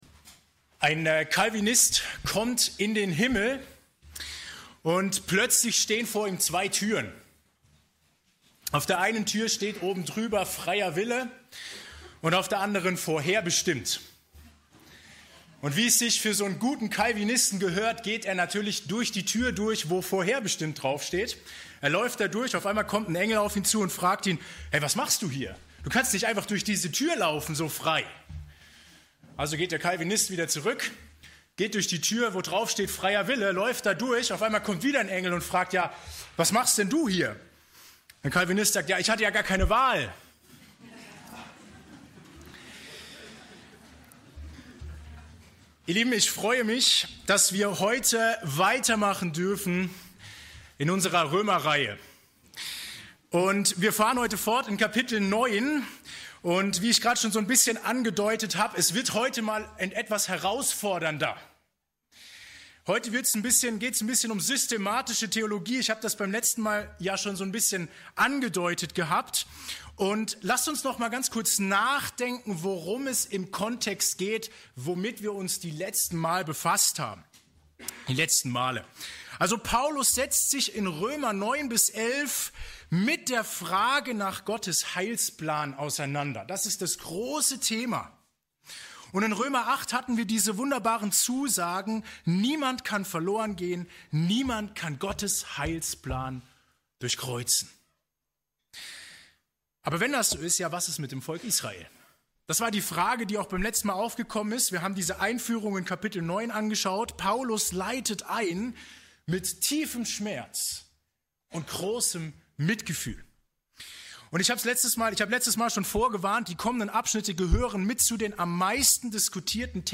Kategorie: Gottesdienst